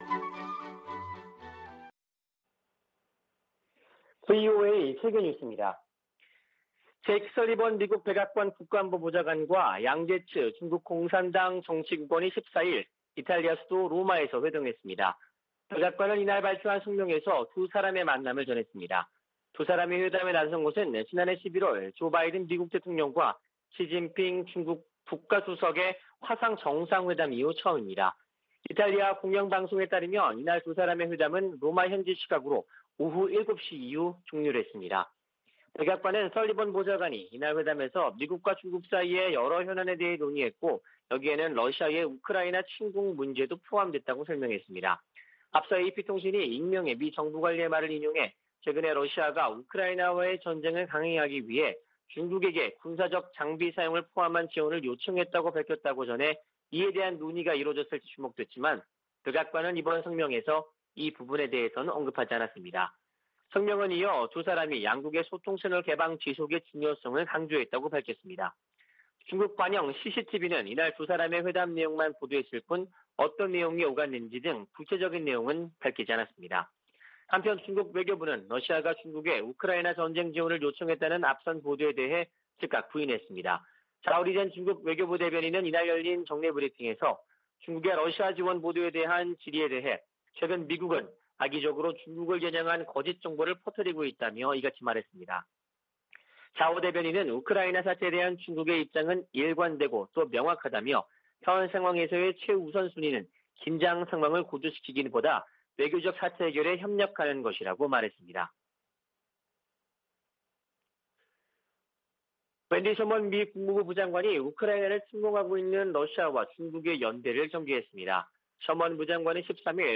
VOA 한국어 아침 뉴스 프로그램 '워싱턴 뉴스 광장' 2022년 3월 15일 방송입니다. 성 김 미 대북특별대표가 중국에 북한이 도발을 중단하고 대화에 복귀하도록 영향력을 발휘해 달라고 요구했습니다. 미 국무부는 북한 탄도미사일 발사 등이 역내 가장 긴박한 도전이라고 지적하며 한국 차기 정부와의 협력을 기대했습니다. 미-한 군 당국은 북한이 신형 ICBM 성능 시험을 위한 추가 발사를 준비하는 징후를 포착하고 대비태세를 강화하고 있습니다.